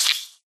Sound / Minecraft / mob / silverfish / hit3.ogg
hit3.ogg